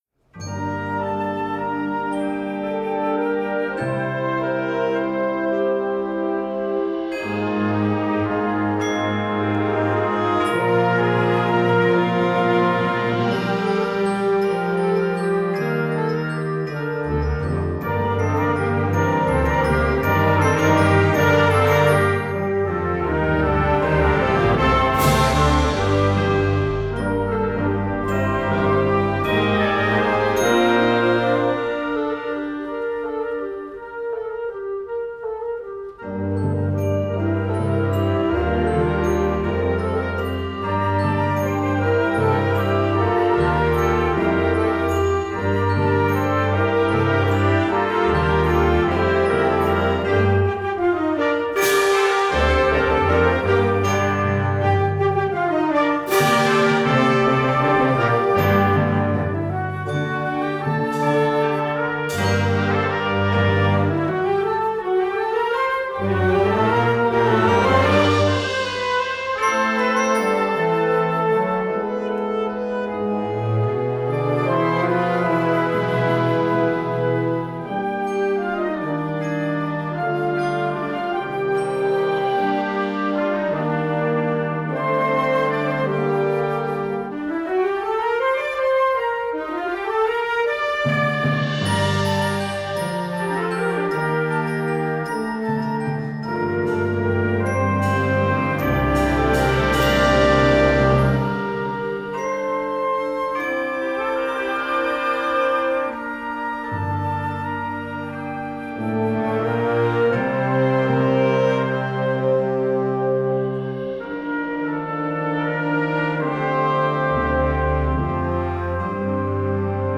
Gattung: Weihnachtslied für Jugendblasorchester
Besetzung: Blasorchester
Glocken, Weihnachtslieder und Fröhlichkeit.